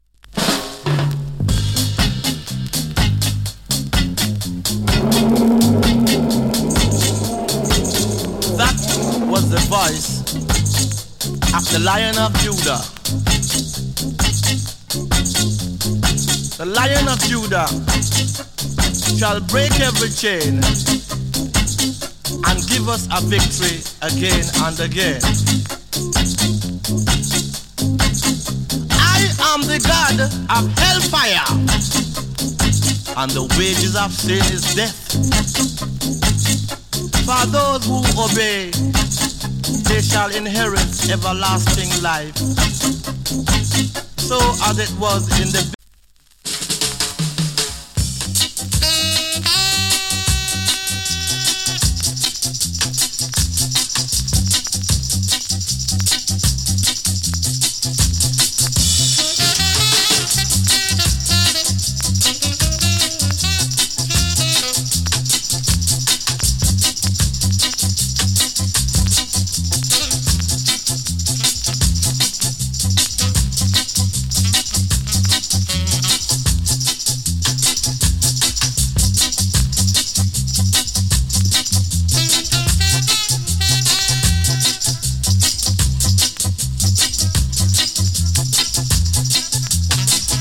チリ、パチ、ジリノイズ少し有り。